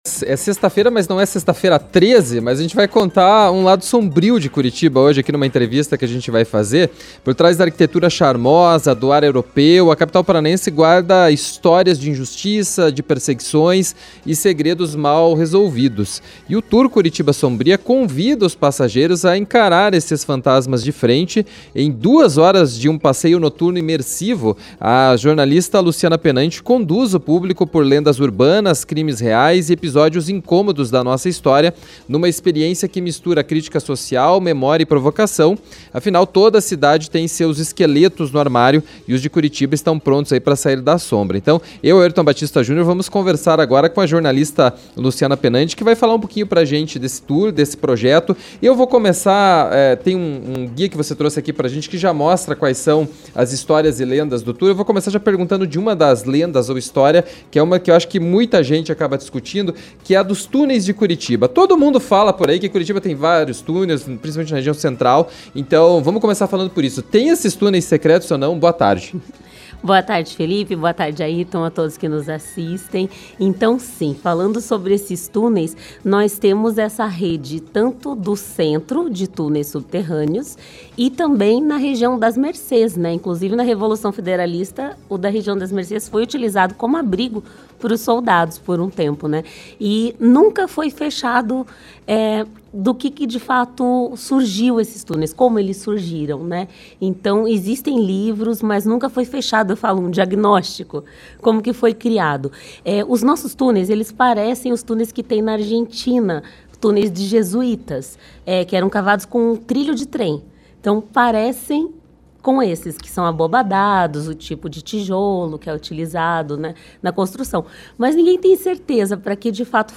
receberam no estúdio